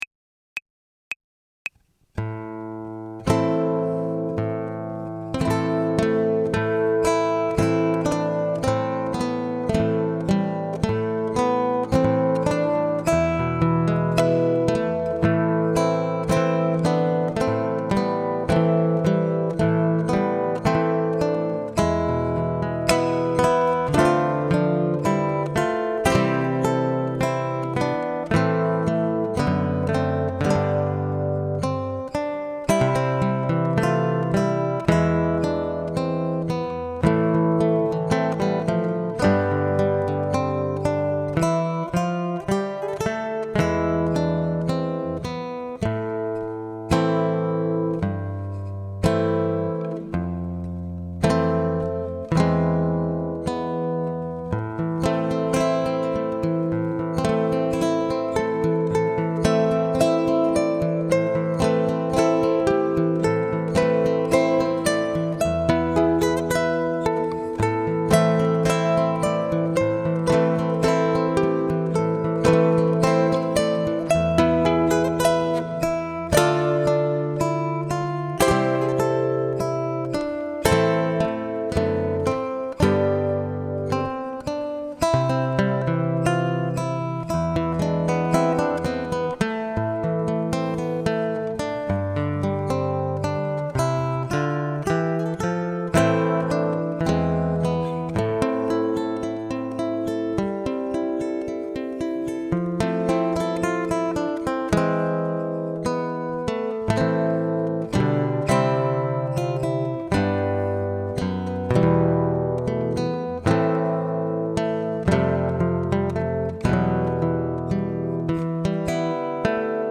Ensembles include audio backing tracks.